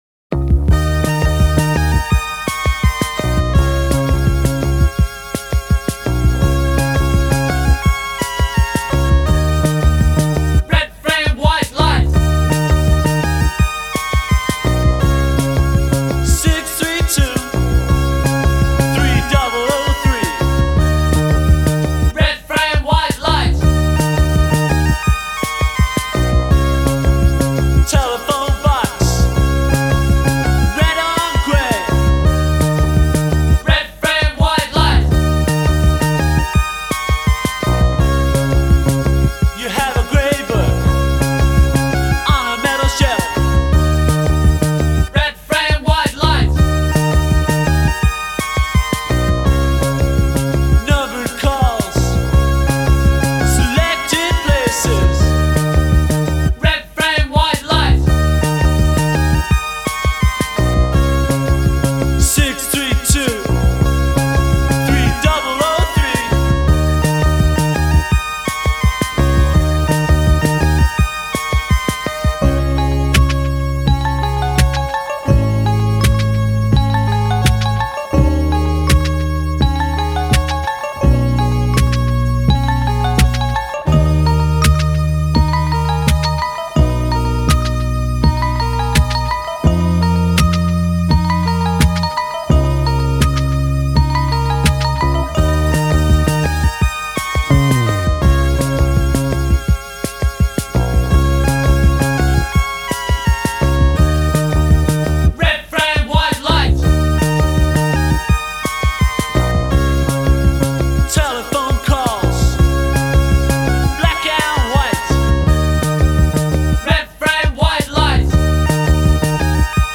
EDM at the 80s forefront.
And even the beat was synthesized.